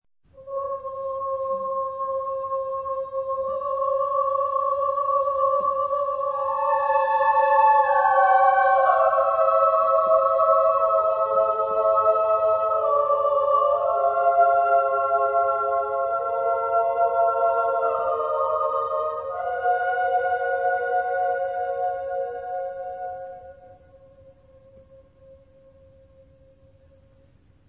Genre-Stil-Form: Motette ; Zyklus ; weltlich
Chorgattung: SSAATTBB  (8 gemischter Chor Stimmen )
Solisten: Sopran (1)  (1 Solist(en))
Tonart(en): zwölftönig ; frei
Aufnahme Bestellnummer: 3.Deutscher Chorwettbewerb, 1990